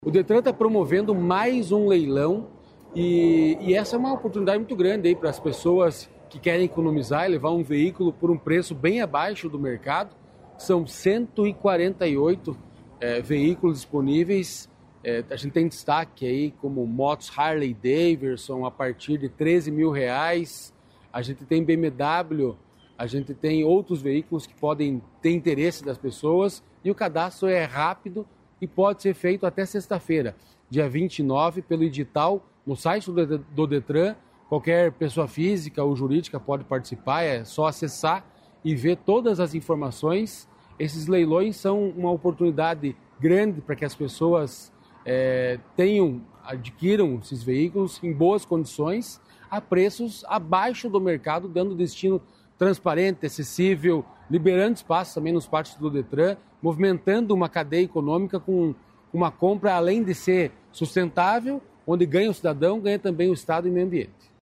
Sonora do presidente do Detran-PR, Santin Roveda, sobre o leilão